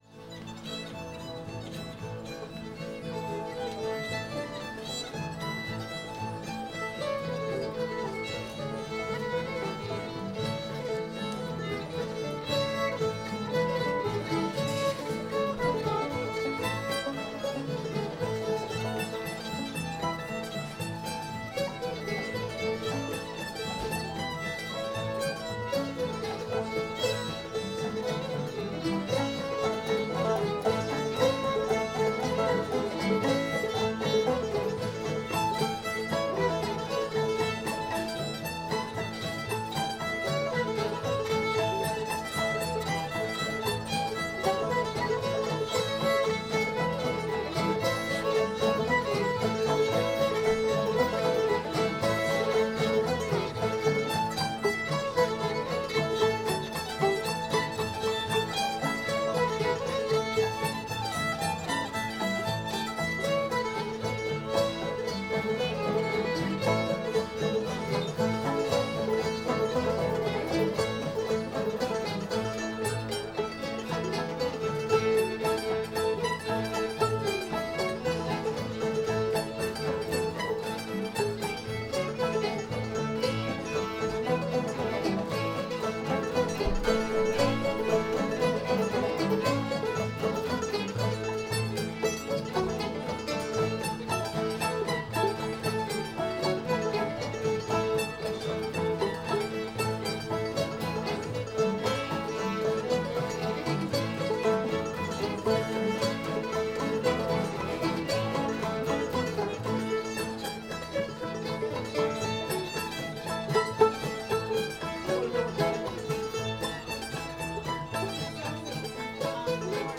cookhouse joe [A]